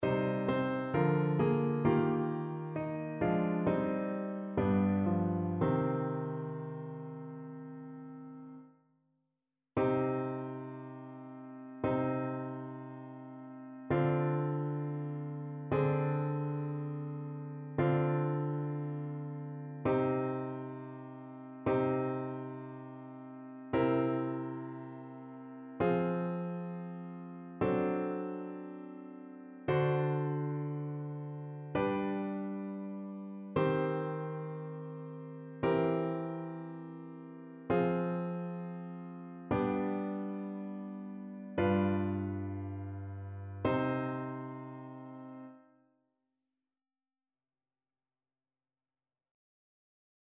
ChœurSoprano